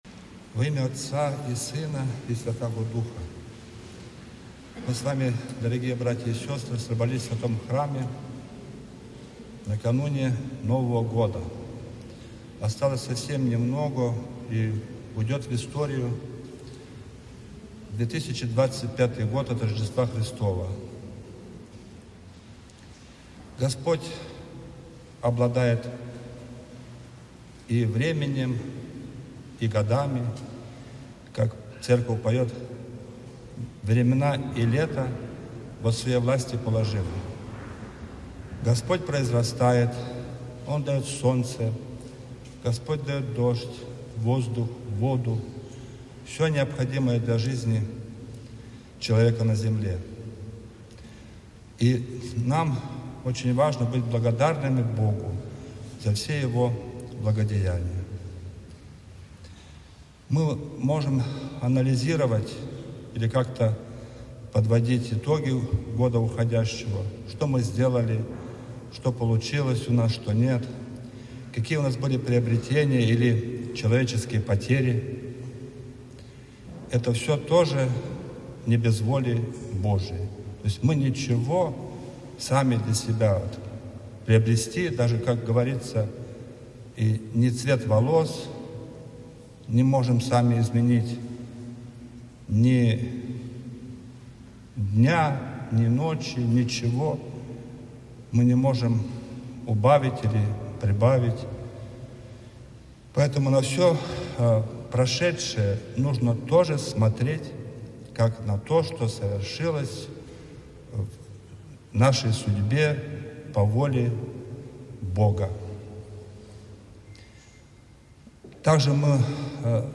Новогодний-молебен.mp3